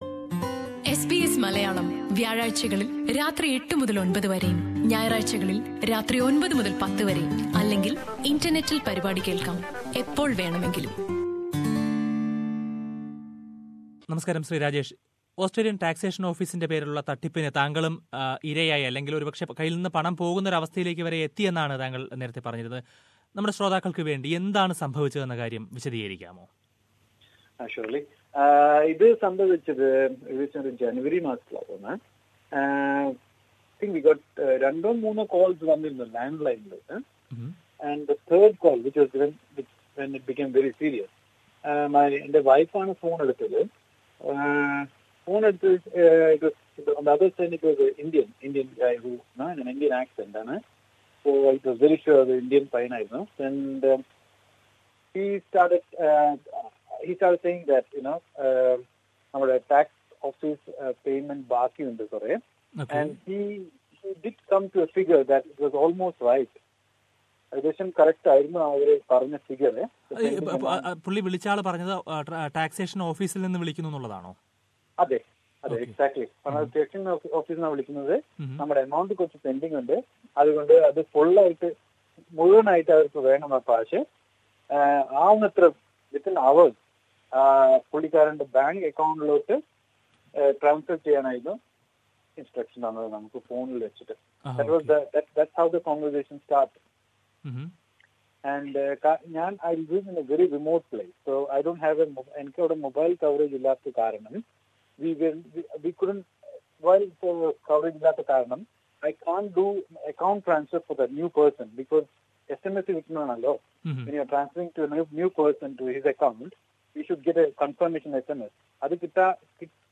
ഈ തട്ടിപ്പിൻറെ രീതിയെക്കുറിച്ച് അറിയുന്നതിനായി ഇത്തരത്തിൽ ഫോൺ കോൾ കിട്ടിയ ഒരു മലയാളിയുമായി നമ്മൾ സംസാരിക്കുന്നു.